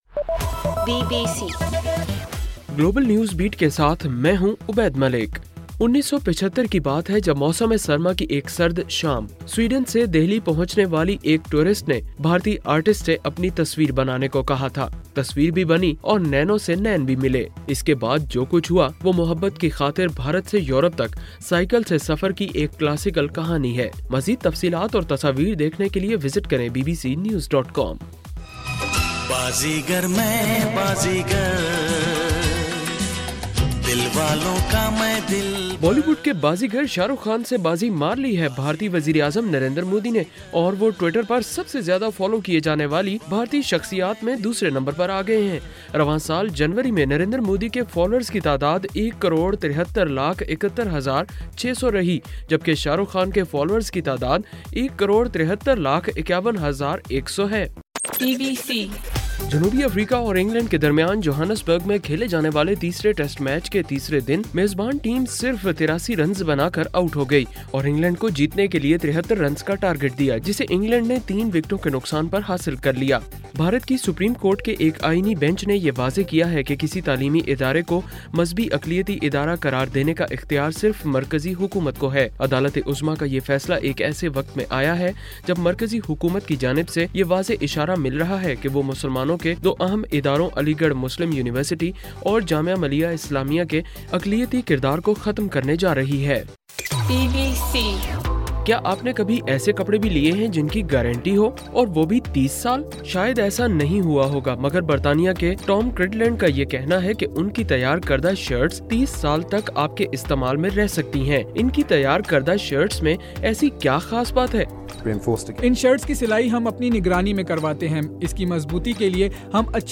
جنوری 17: صبح 1 بجے کا گلوبل نیوز بیٹ بُلیٹن